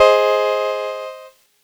Cheese Chord 02-G#2.wav